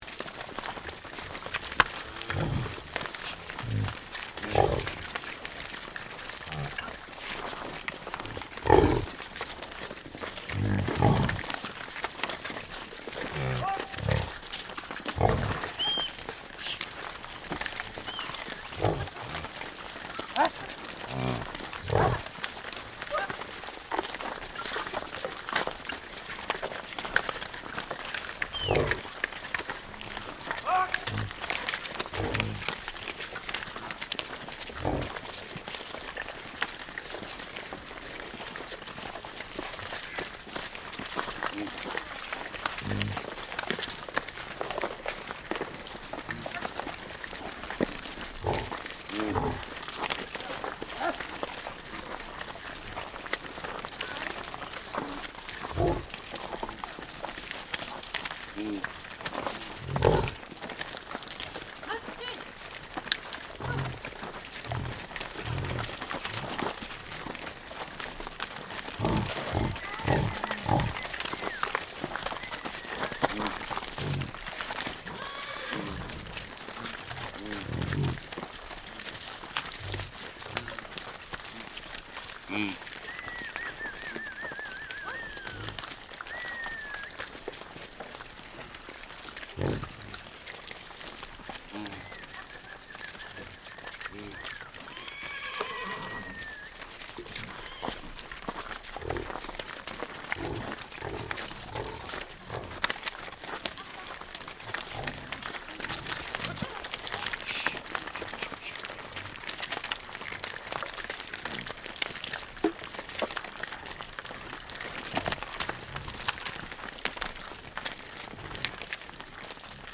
All the others are riding or walking behind the animals. Their calls and shouts spread in the mountain. The yaks and sheep footsteps were crashing the frozen ground or ice.